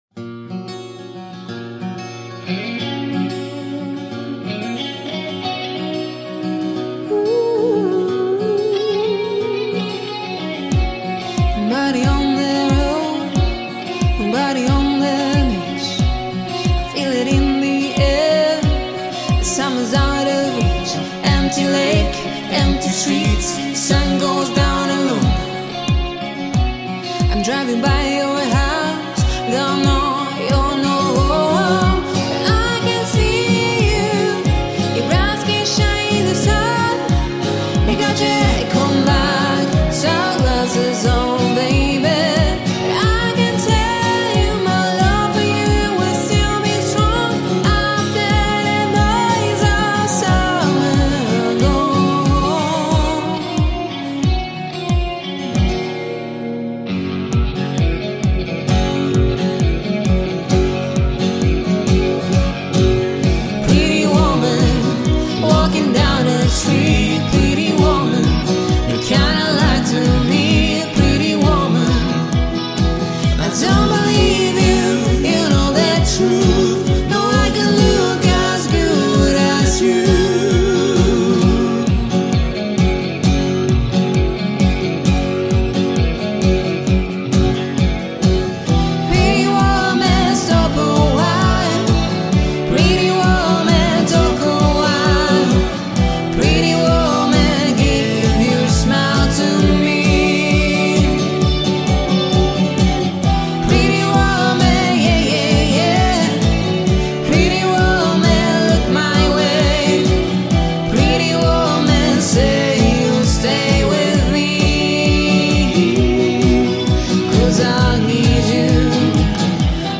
Vibrant, country-rock acoustic duo based in Glasgow.
a powerful & soulful acoustic duo